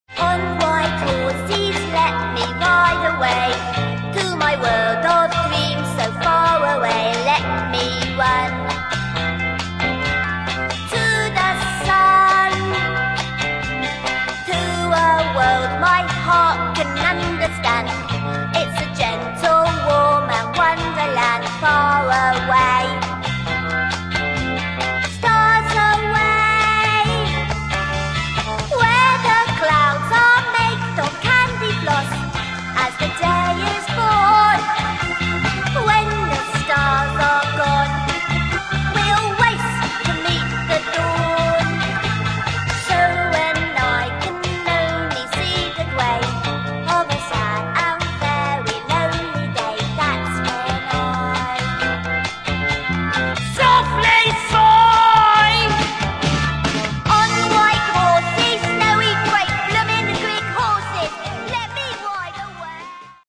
comedy single